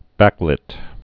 (băklĭt)